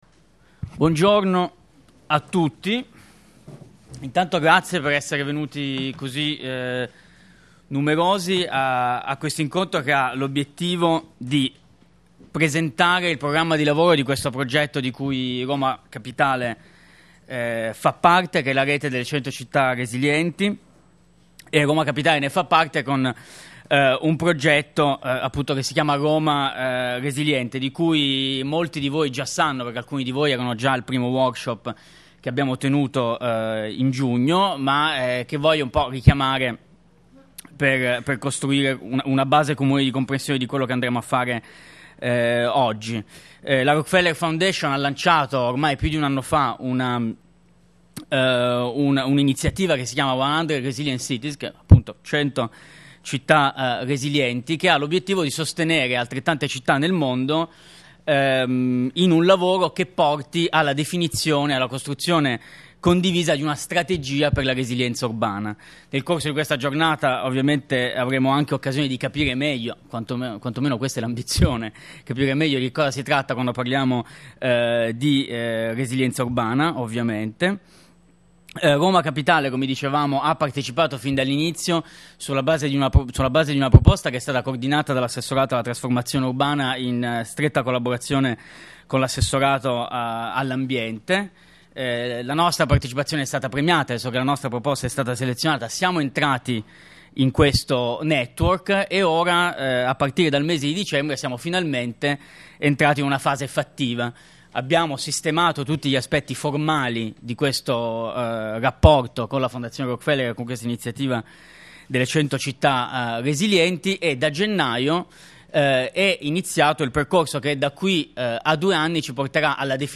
Audio integrale della sessione introduttiva della giornata